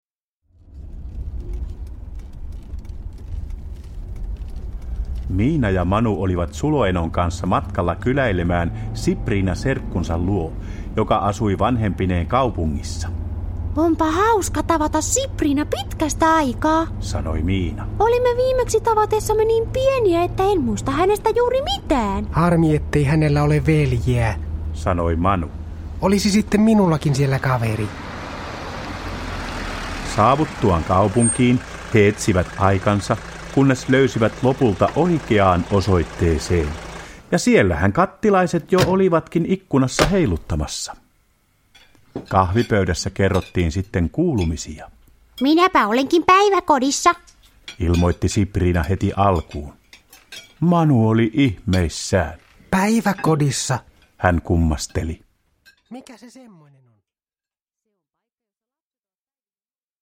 Miina ja Manu päiväkodissa – Ljudbok – Laddas ner